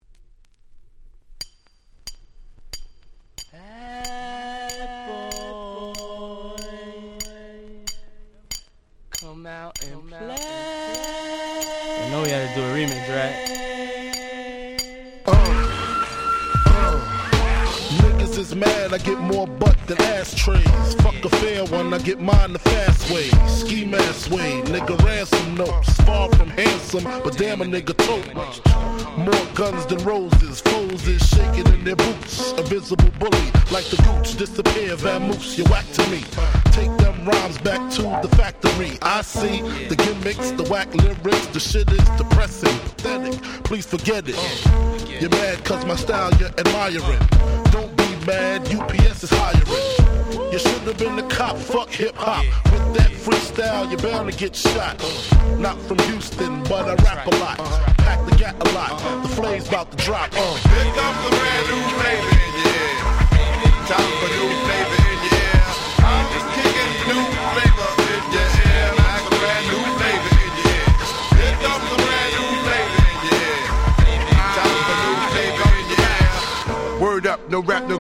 90's Hip Hop Super Classics !!